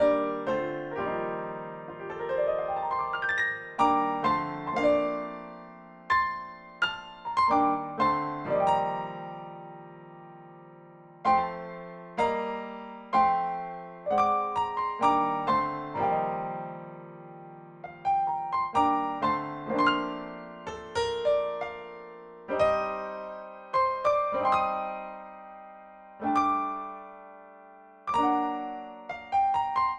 07 piano D.wav